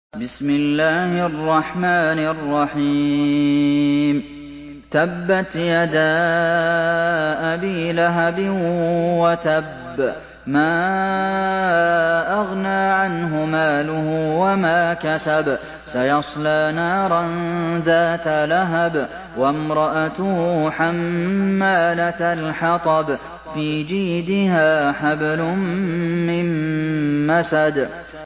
Surah المسد MP3 in the Voice of عبد المحسن القاسم in حفص Narration
مرتل